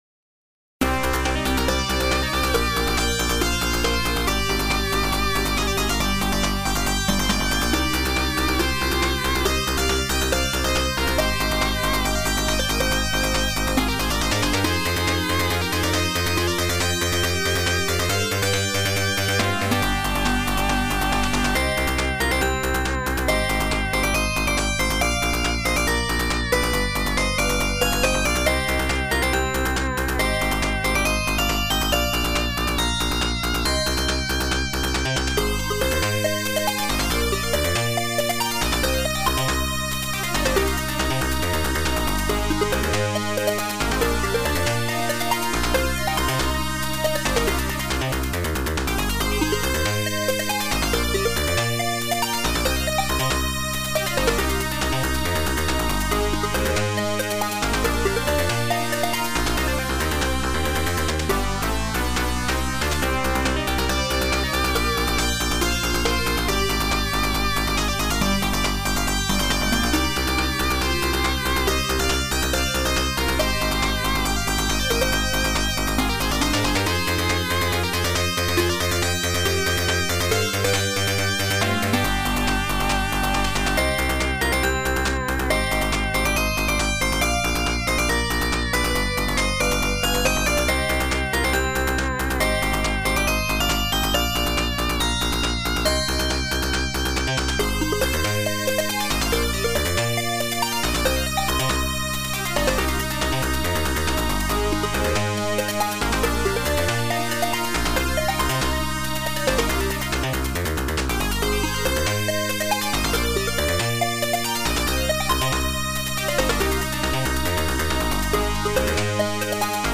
ここではMUCOM88winで作ったMUCソースとMP3化したファイルを掲載しています。